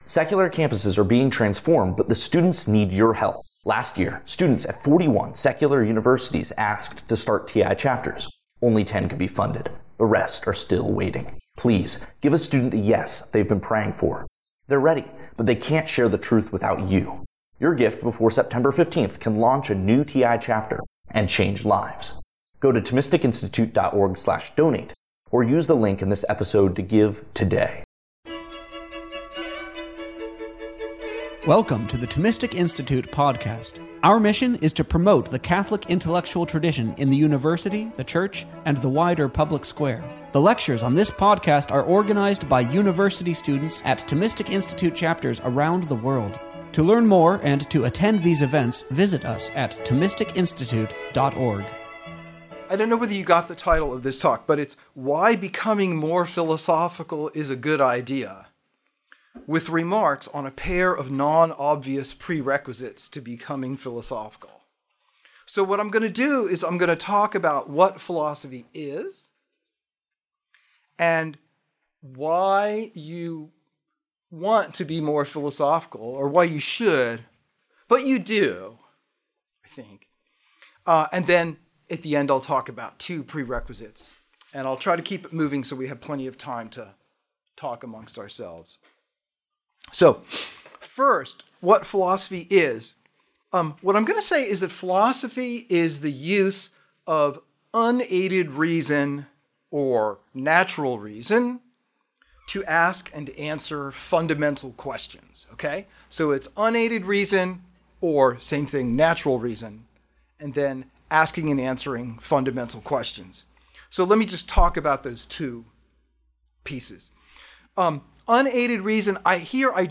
This lecture was held on October 17th, 2018 at the Catholic Information Center, Washington DC.